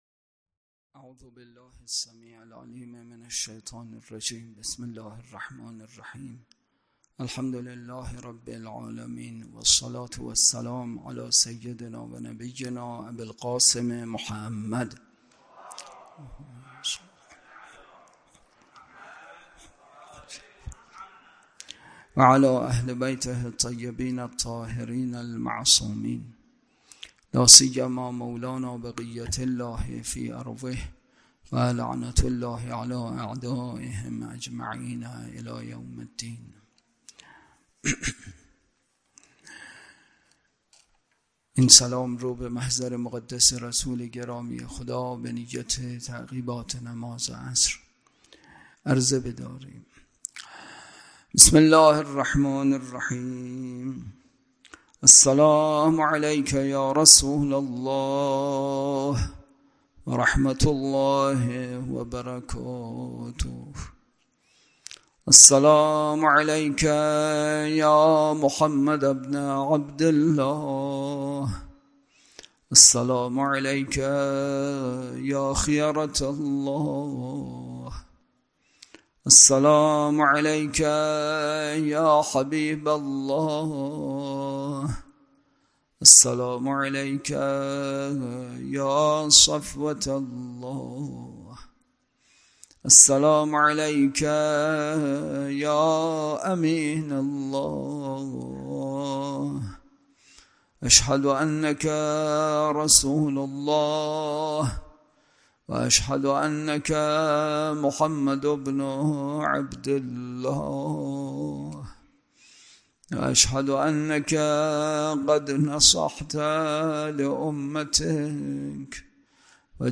اطلاعات آلبوم سخنرانی
برگزارکننده: مسجد اعظم قلهک